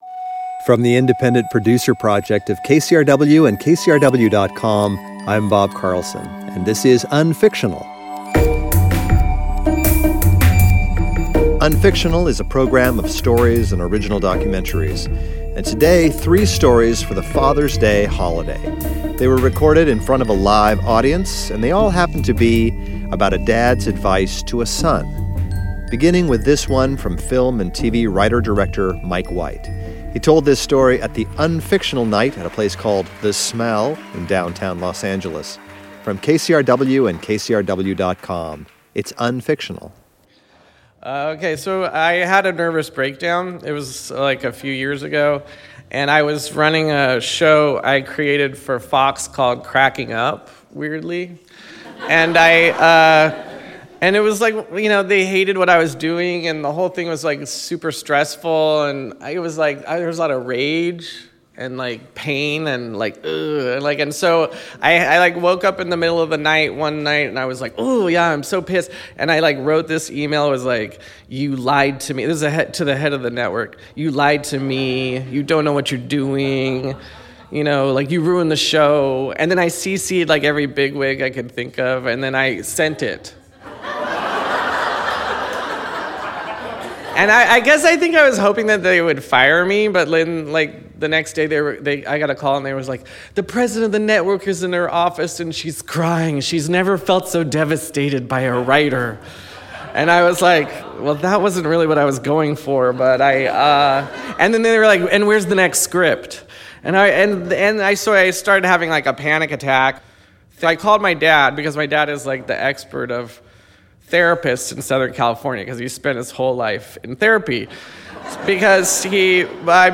This is the second episode of stories recorded live at the recent Riot LA Alternative Comedy festival. It turned out that three of the storytellers had great stories about a moment with their dad’s that carried great meaning.